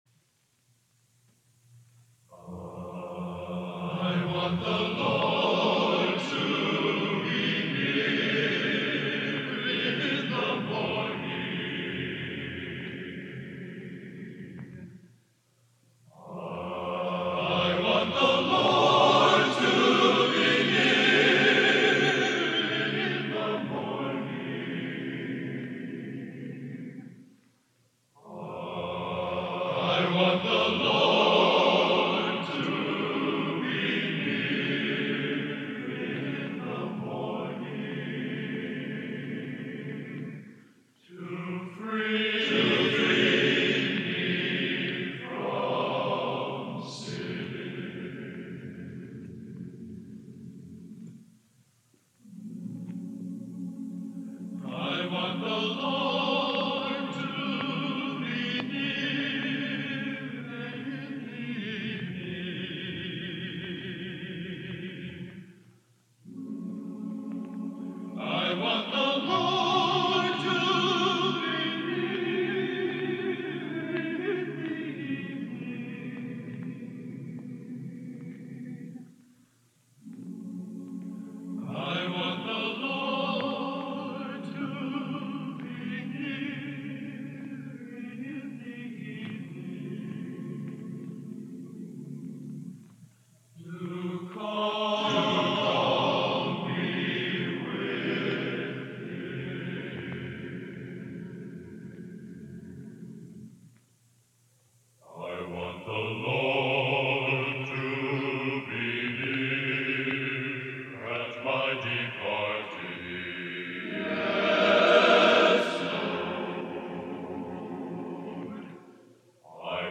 Genre: Classical Sacred | Type: Studio Recording